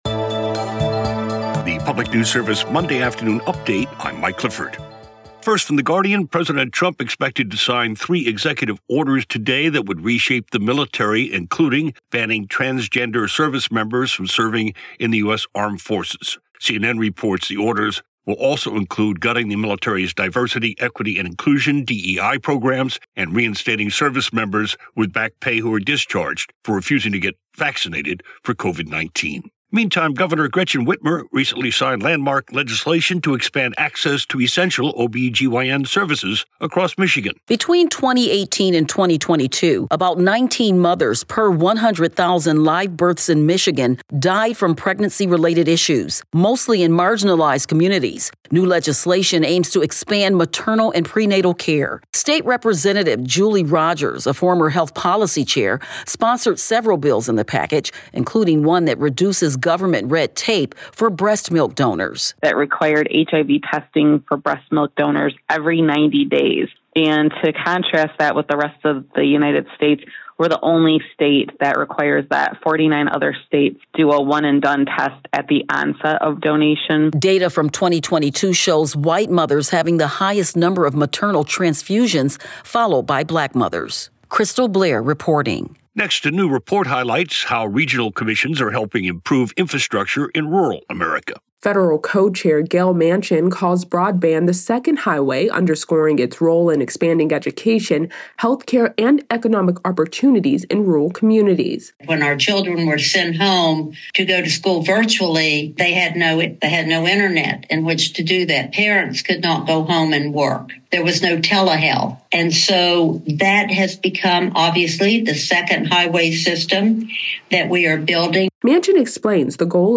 Evening news report for Monday, January 27, 2025